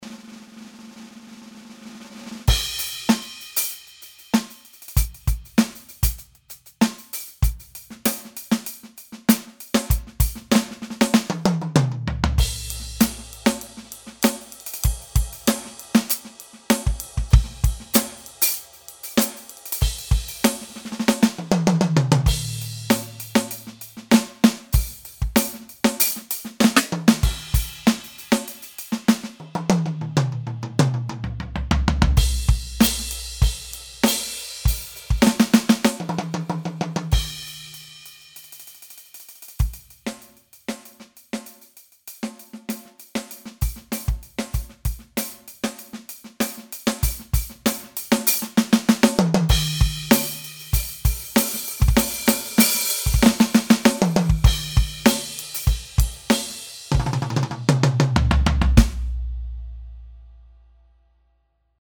Hab versucht möglichst dynamisch zu spielen